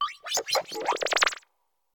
Cri de Germéclat dans Pokémon Écarlate et Violet.